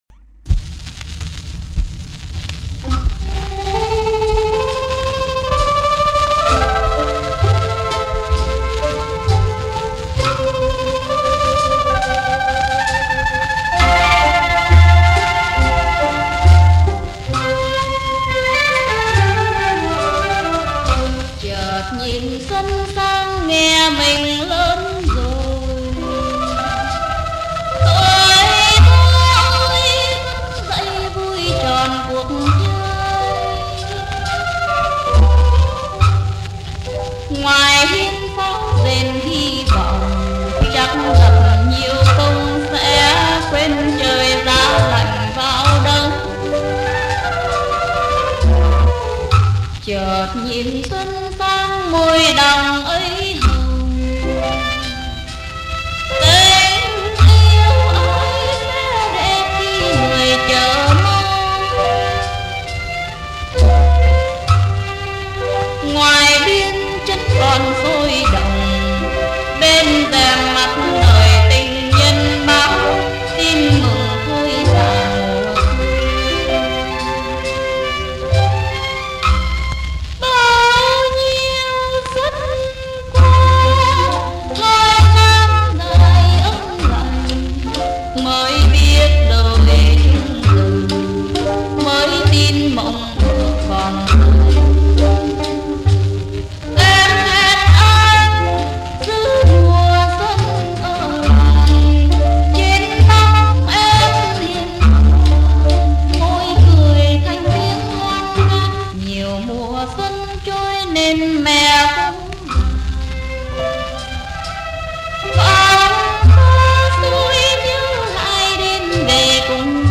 dia nhua 45 vong